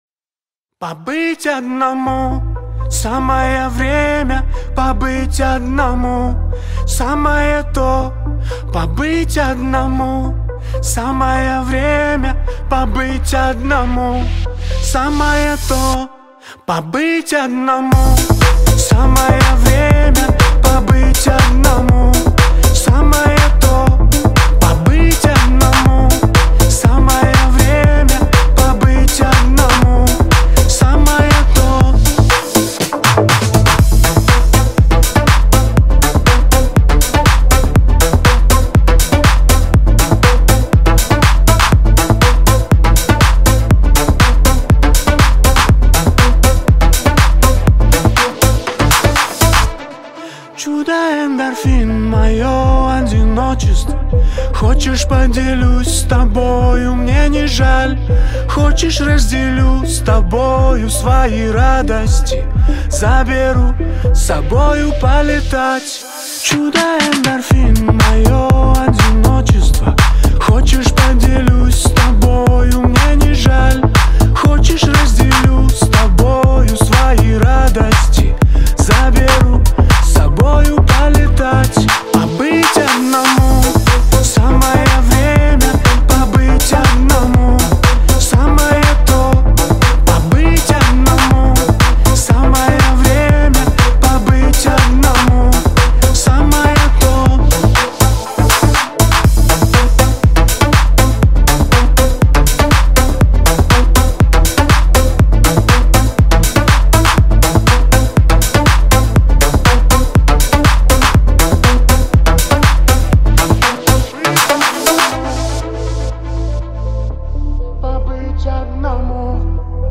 • Жанр: Русские песни